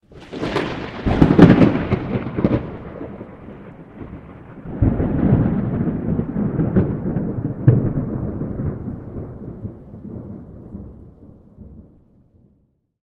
Divergent/thunder_16.ogg at 255081e1eea8a9d8766ef0be22fed2081c66c9e2
thunder_16.ogg